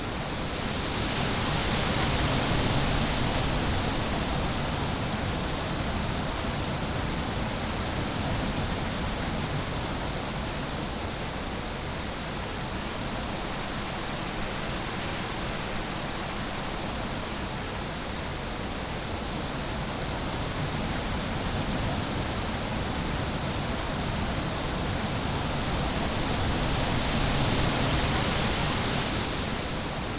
Wind Snow.ogg